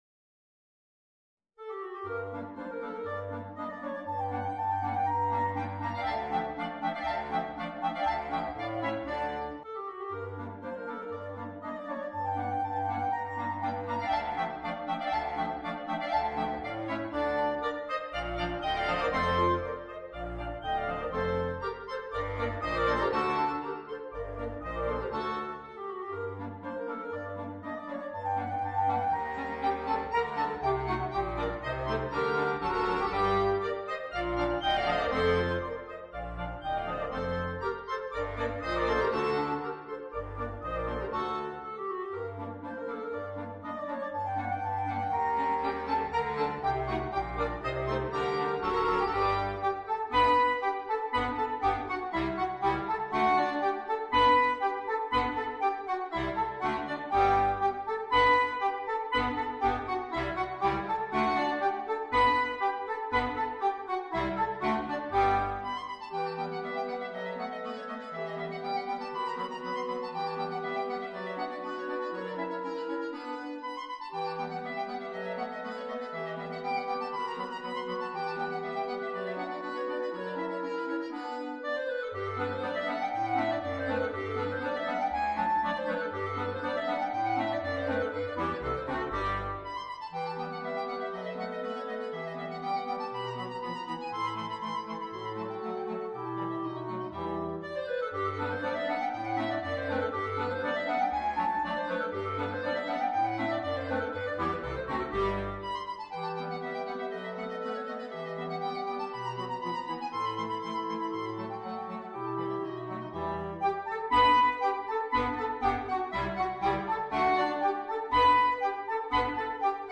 trascrizione per coro di clarinetti